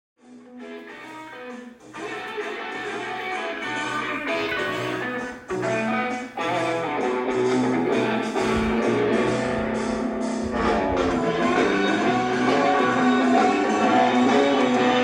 My Crosley CR10 50s style Radio with Cassette from 2005